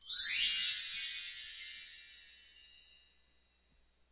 钟声" 钟声4+秒滑行起来
描述：在LP双排钟声树上上升的滑音。在我的衣橱里用雅马哈AW16G和廉价的舒尔话筒进行录音
标签： 打击乐器 风铃 滑奏 管弦乐 风铃 风铃
声道立体声